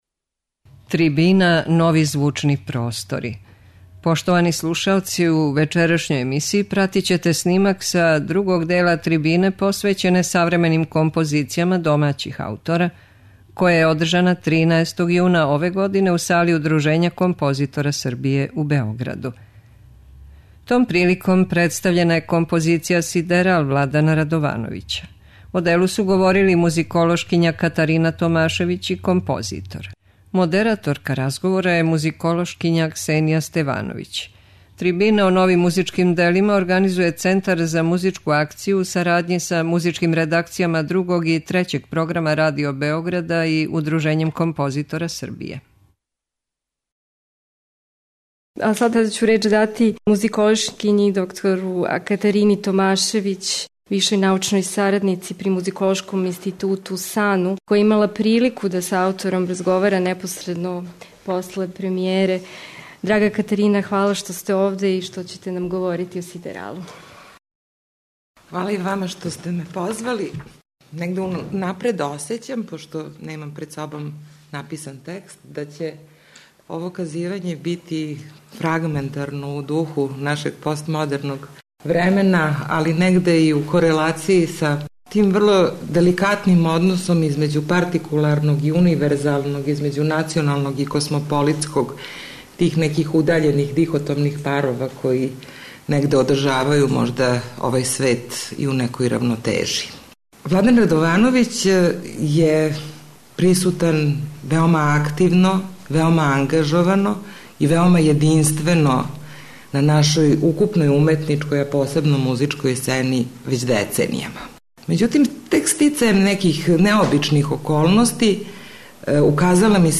Слушаћете снимак са другог дела трибине посвећене савременим композицијама домаћих аутора, која је одржана 13. јуна ове године у Сали Удружења композитора Србије у Београду.